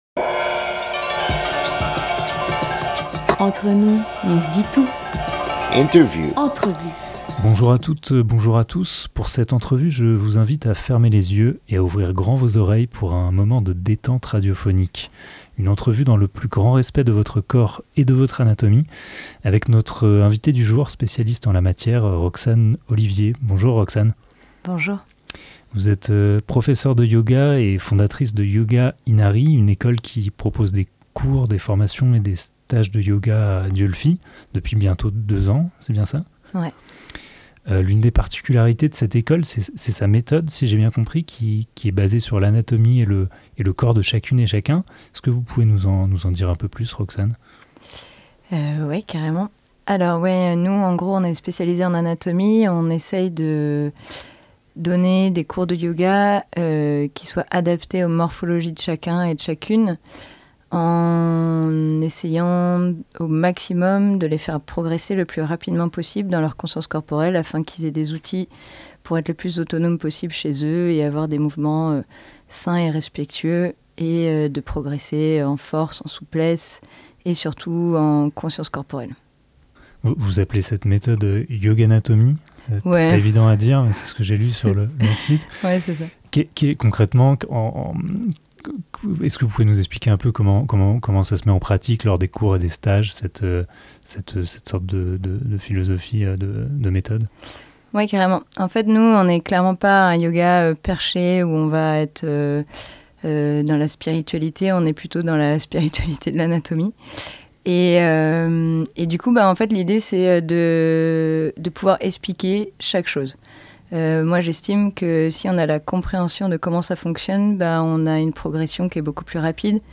10 octobre 2022 15:46 | Interview